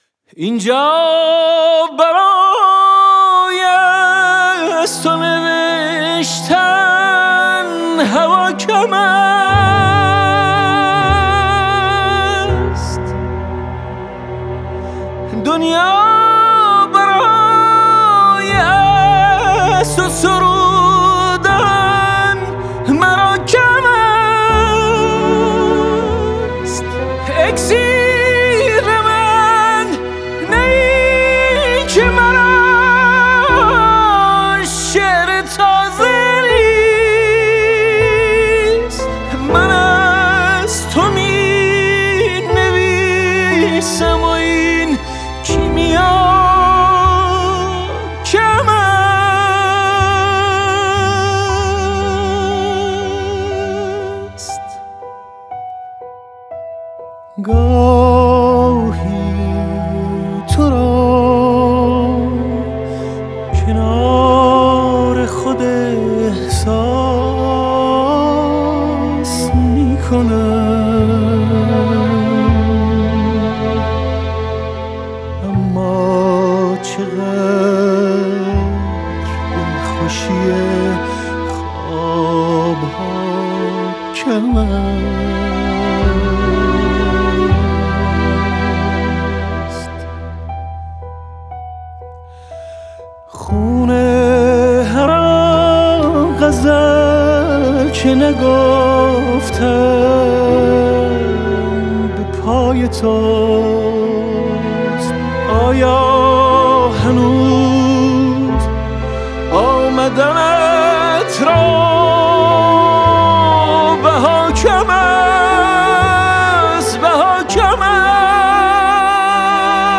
خواننده برجسته موسیقی سنتی
آهنگ غمگین